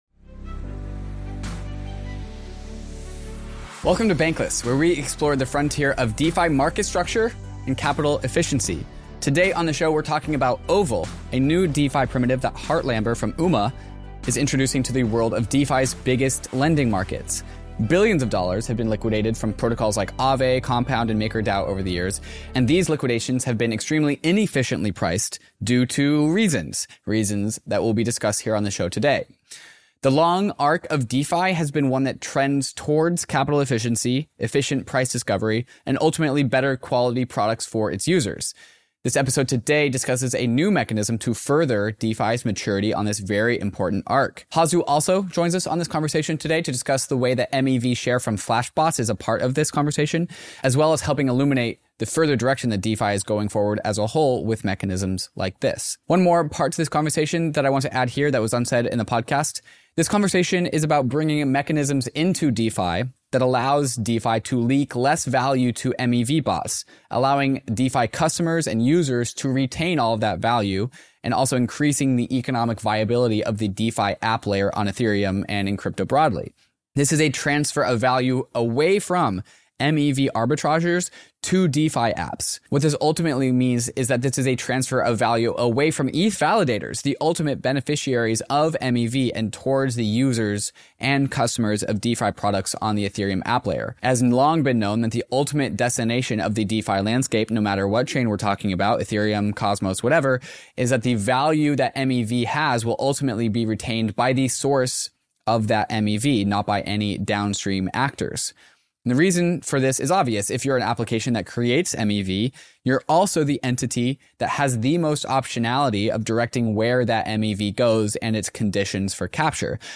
They address the inefficiencies in liquidation pricing that have cost billions and explore how maximally extractable value (MEV) can benefit users rather than just bots. The conversation also sheds light on auction mechanisms, decentralized finance's future, and the critical balance between efficiency and sustainability in DeFi. 01:09:51 share Share public Creator website